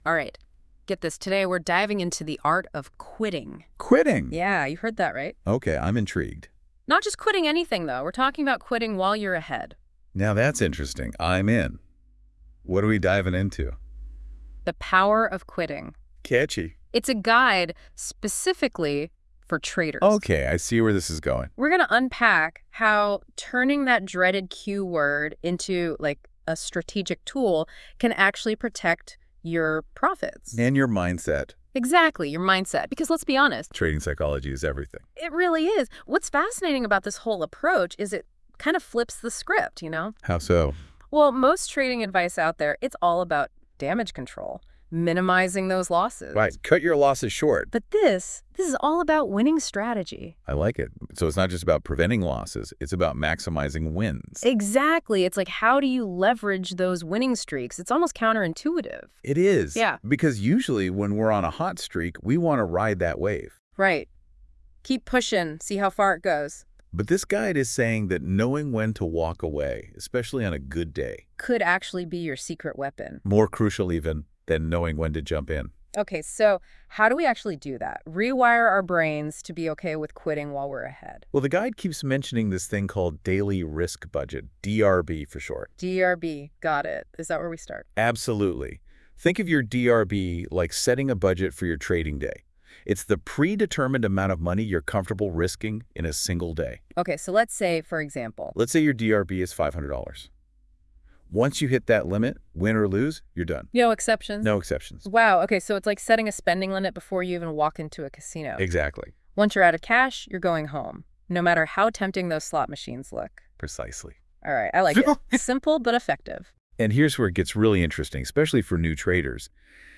We even recorded a podcast episode diving deeper into this topic—if you prefer a chat-style approach, check that out!Why Quitting Matters, Especially When You’re Up (An AI Podcast)